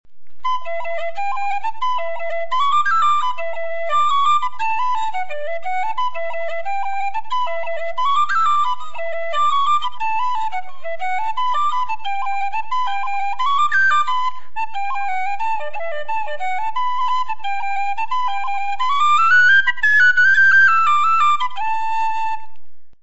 Je joue un peu plus vite que dans les exemples précédents, mais vous devriez malgré tout être capable de distinguer ce bon vieux "da-bla-bla".
Dans la deuxième mesure avant la fin, il y a un long roll sur Sol aigu.
Par conséquent, pour le mettre un peu plus en valeur, j'ai fait précéder le roll par un slide Fa#-Sol.
castlereel.mp3